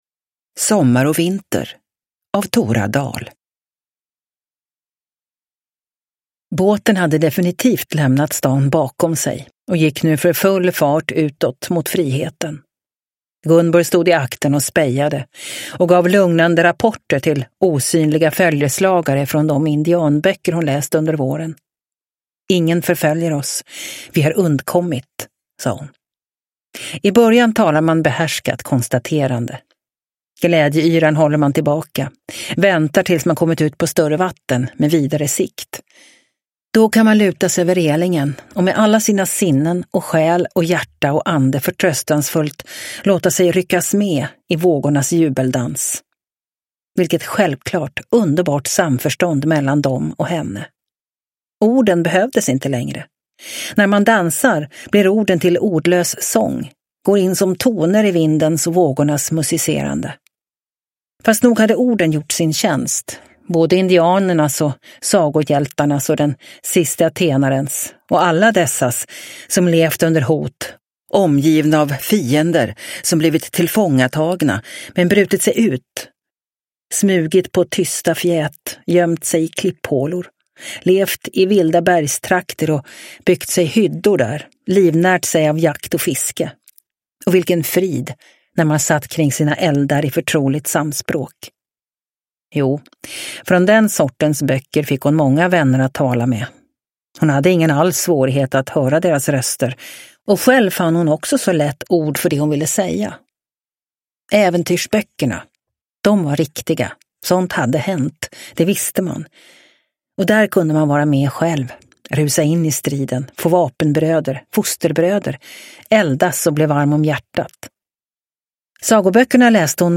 Sommar och vinter – Ljudbok – Laddas ner